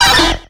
Cri de Têtarte dans Pokémon X et Y.